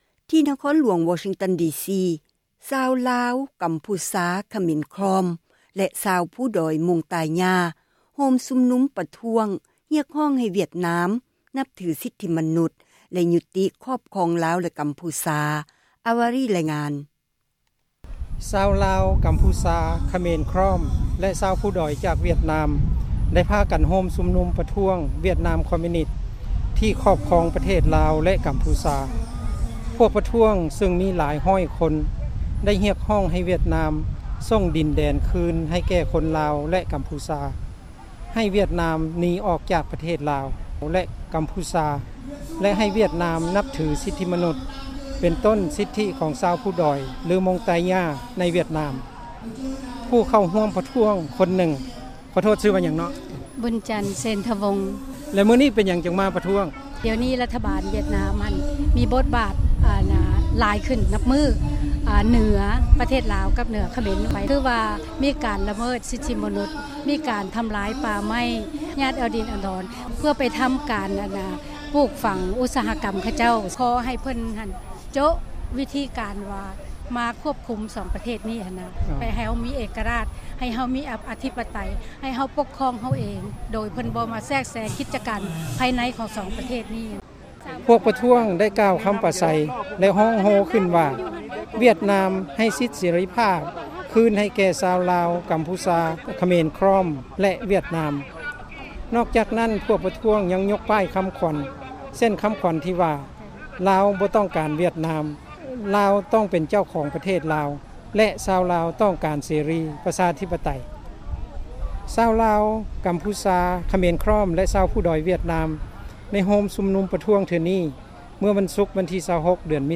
F-Laos-Cambodia ລາວ,ກຳພູຊາ,ຂເມນຂຣອມ ຕໍ່ຕ້ານ ການຮຸກຮານ ຂອງ ວຽດນາມ ຢູ່ຕໍ່ໜ້າຕຶກ ຣັຖສະພາ ສະຫະຣັຖ ນະຄອນຫລວງ ວໍຊິງຕັນ ດີຊີ
ພວກ ປະທ້ວງ ໄດ້ກ່າວ ຄຳປາໄສ ແລະ ຮ້ອງໂຮ ວ່າ, ວຽດນາມ ໃຫ້ ສິດ ເສຣີພາບ ຄືນ ໃຫ້ແກ່ ຊາວລາວ ກຳພູຊາ ແລະ ວຽດນາມ.